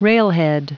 Prononciation du mot railhead en anglais (fichier audio)
Prononciation du mot : railhead